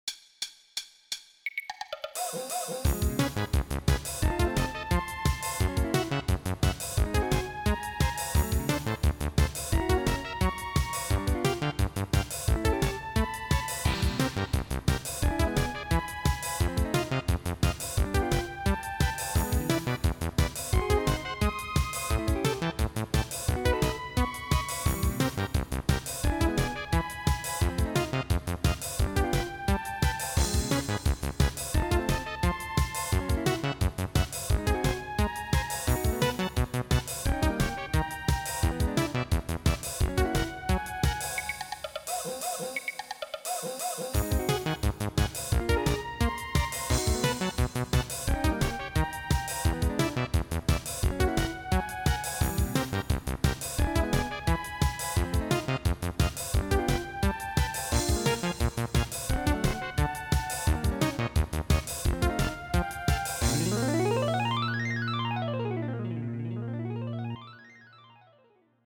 8beat